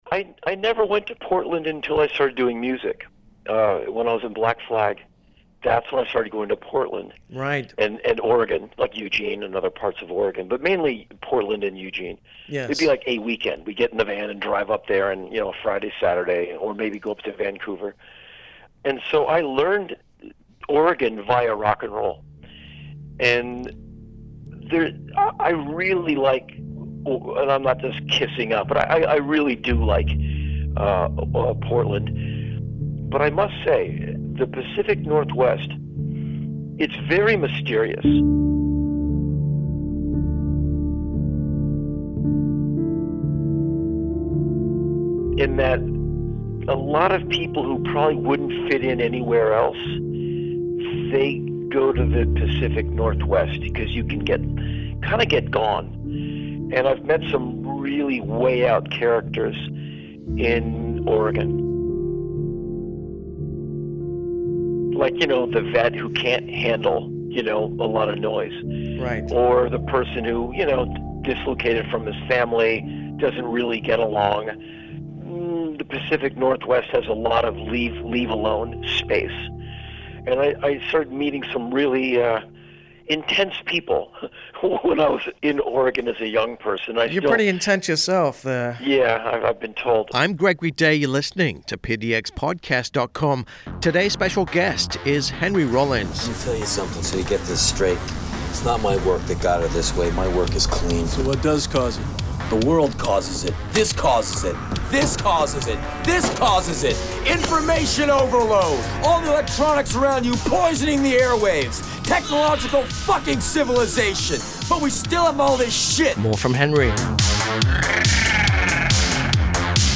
(From 2018) Henry chats with us prior to his last Portland visit.